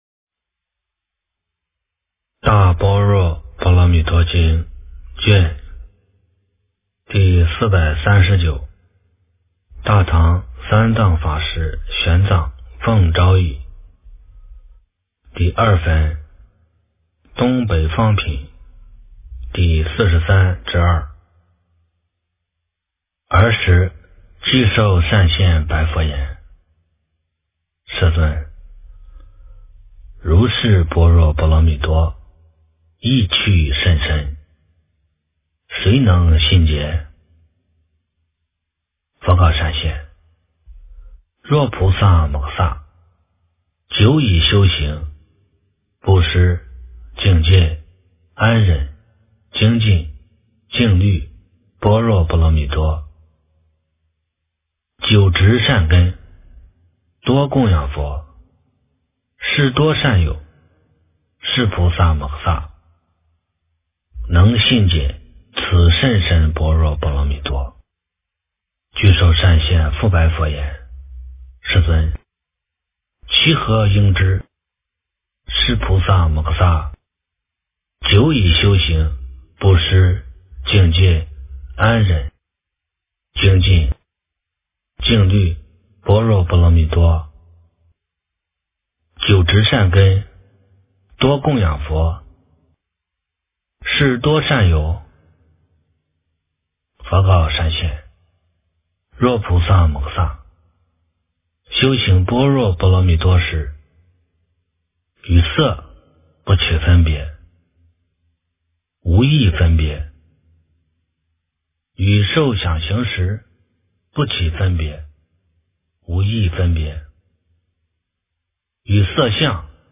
大般若波罗蜜多经第439卷 - 诵经 - 云佛论坛